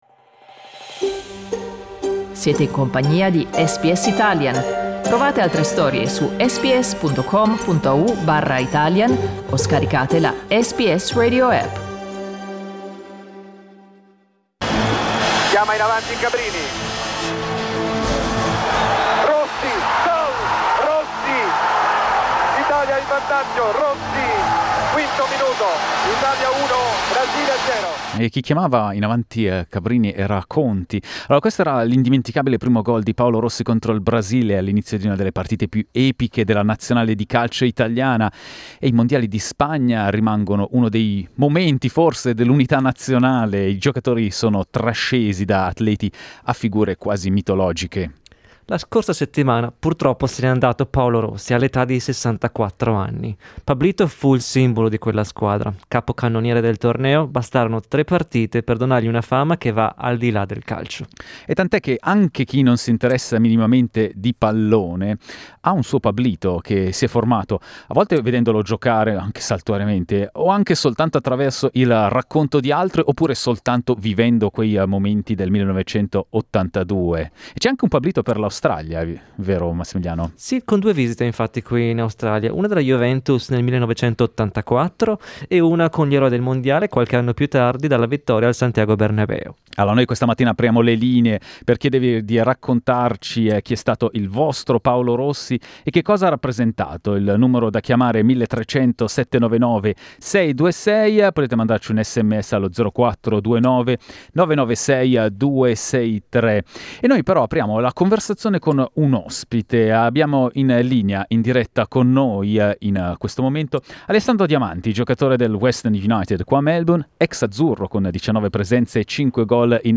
Listen to footballer and family friend Alessandro Diamanti, comedian Santo Cilauro and SBS Italian listeners remembering "Pablito".